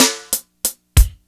BEAT 5 93 04.wav